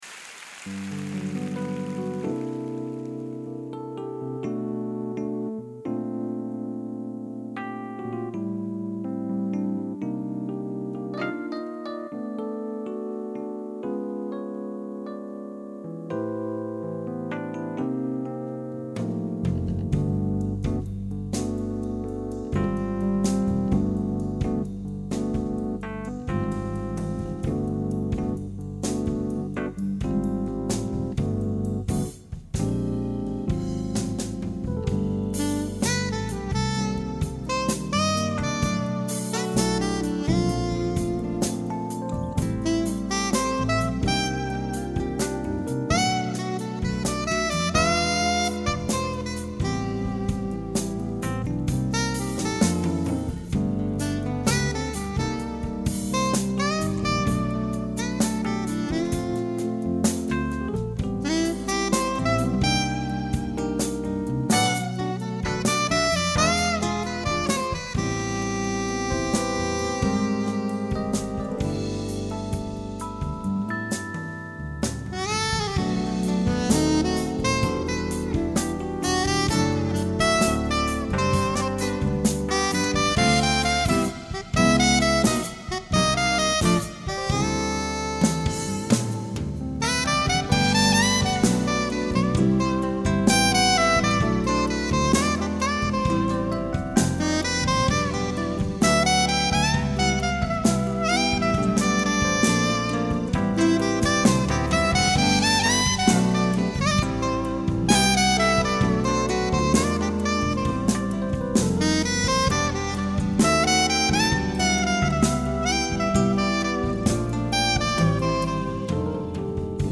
말 그대로 편안함이 느껴지는 곡입니다.